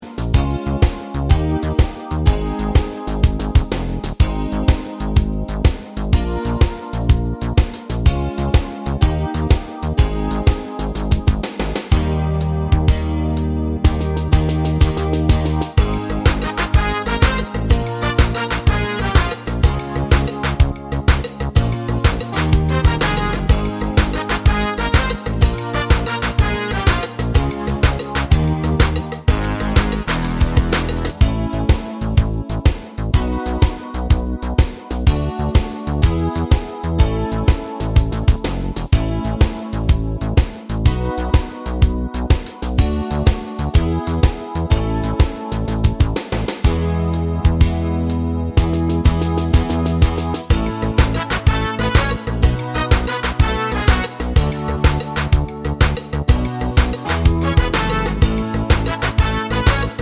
no Backing Vocals Comedy/Novelty 3:08 Buy £1.50